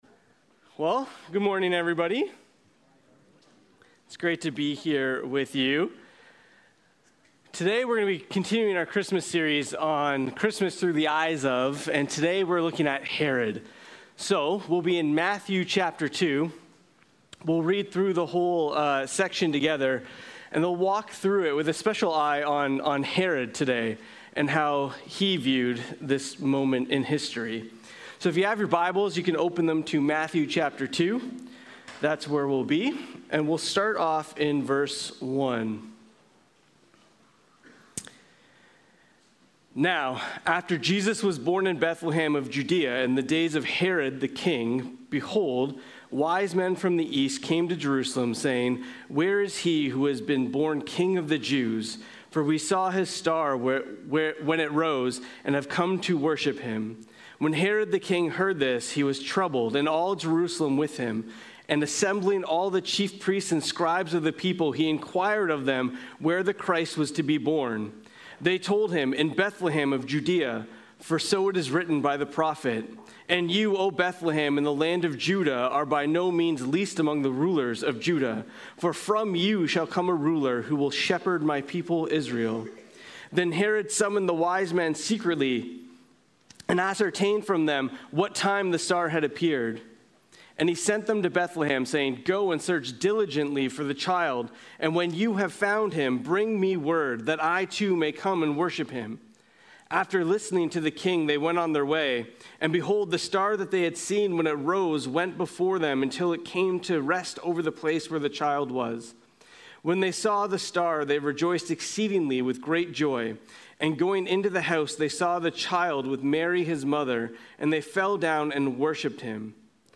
Sermons | James North Baptist Church